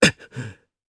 Riheet-Vox_Landing_jp.wav